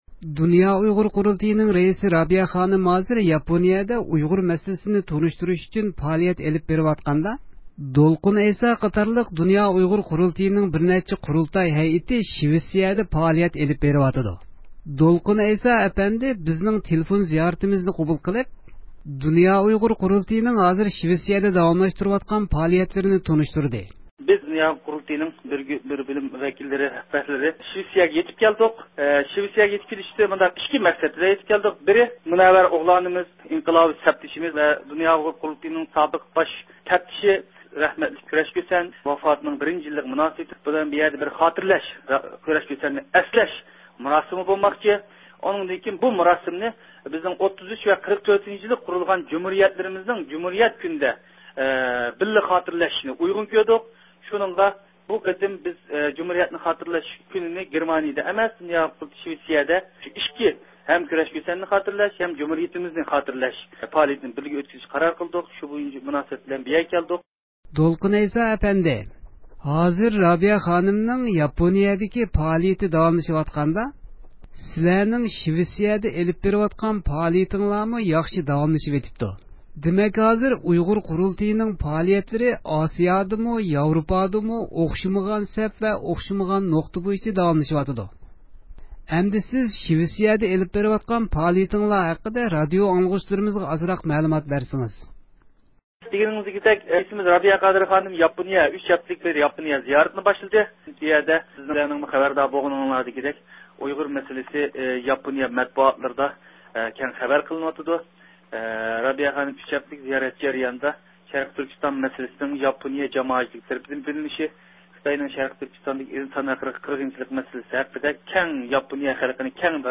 دولقۇن ئەيسا ئەپەندى بىزنىڭ تېلېفون زىيارىتىمىزنى قوبۇل قىلىپ، دۇنيا ئۇيغۇر قۇرۇلتېيىنىڭ ھازىر شىۋىتسىيىدە داۋاملاشتۇرۇۋاتقان پائالىيەتلىرىنى تونۇشتۇردى.